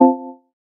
Звуки отмены для монтажа
Звук отмены настроек и возврат к предыдущему экрану